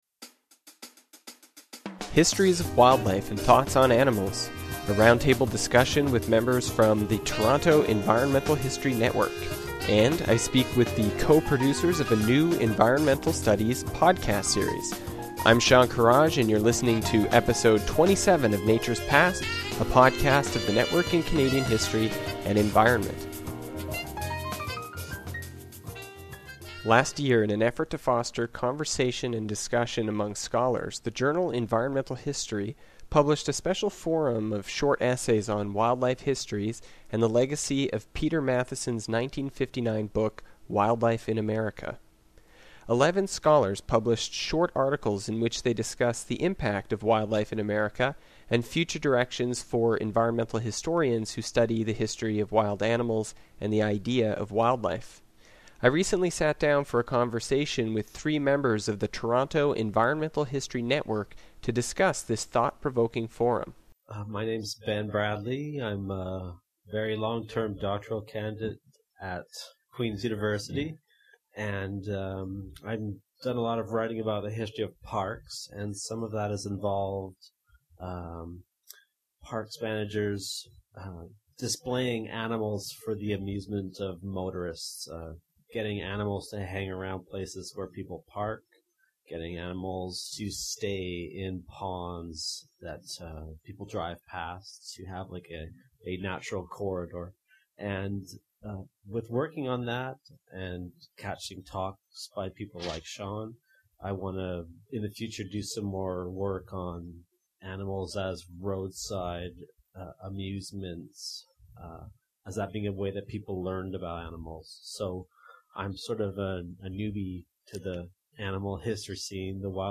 Eleven scholars published short articles in which they discuss the impact of Wildlife in America and future directions for environmental historians who study the history of wild animals and the idea of wildlife. On this episode of the podcast, three members of the Toronto Environmental History Network sit down to share their thoughts on this provocative collection of articles.